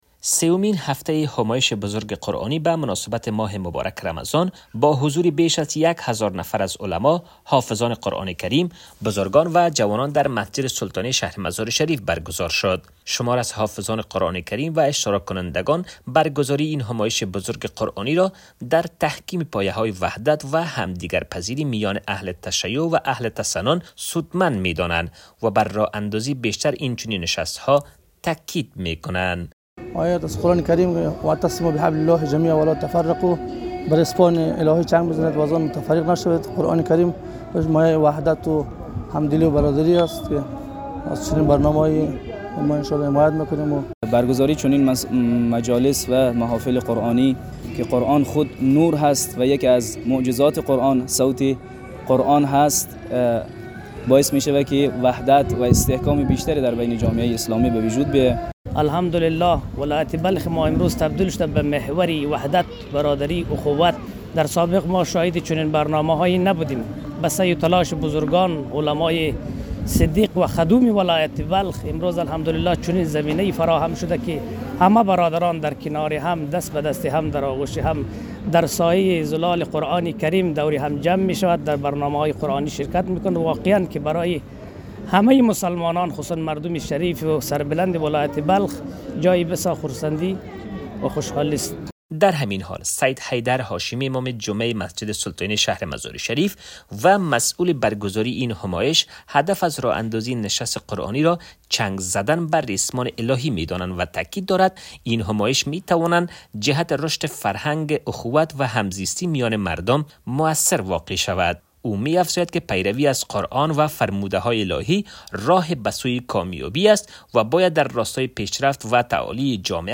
گزارش فرهنگی